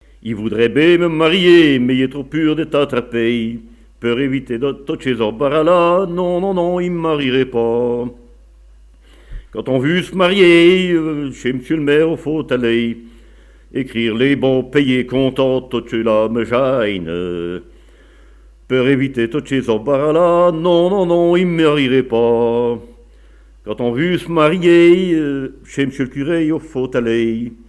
Genre énumérative
Chansons et témoignages sur le chanson et la musique
Pièce musicale inédite